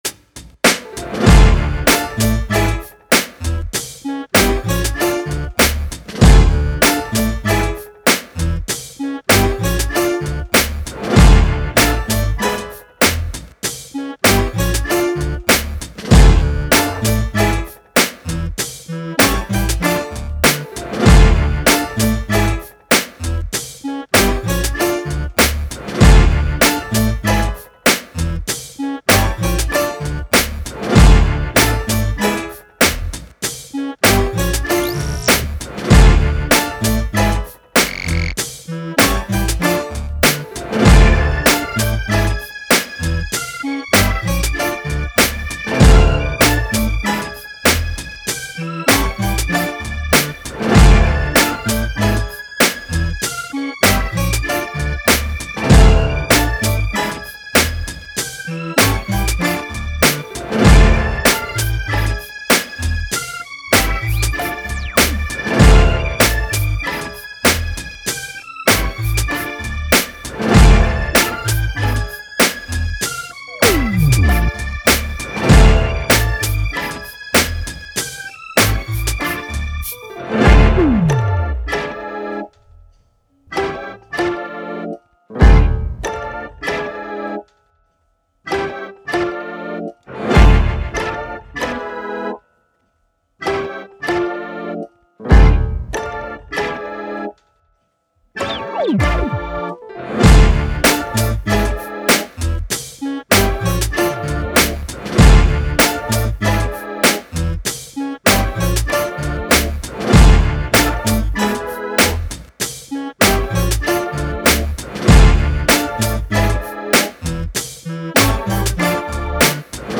Strong, dynamic and punchful hip hop beat.